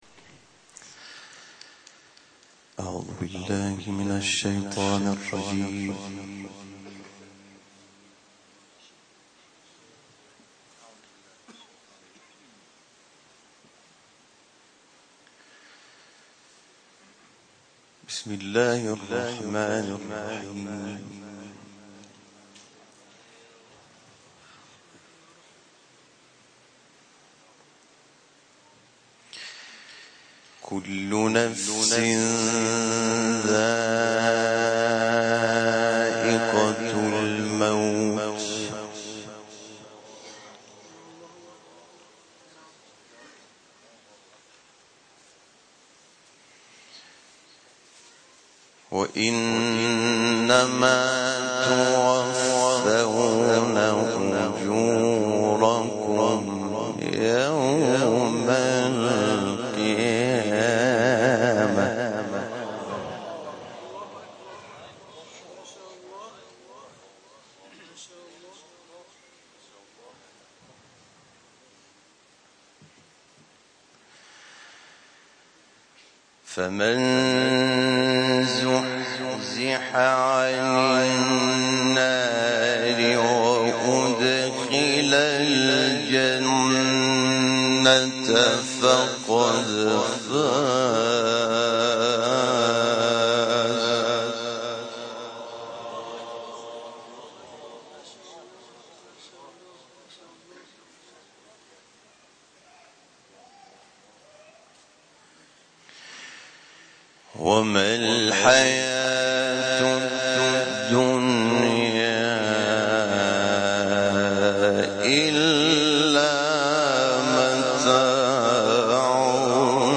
گروه فعالیت‌های قرآنی: حامد شاکرنژاد، قاری بین‌المللی قرآن ضمن گرامیداشت یاد و خاطره جان‌باختگان حادثه کشتی سانچی آیاتی از سوره‌های «آل‌عمران» و «نازعات» را در محفل قرآنی حسینیه قرآن و عترت مشهد تلاوت و به روح این عزیزان تقدیم کرد.
تلاوت حامد شاکرنژاد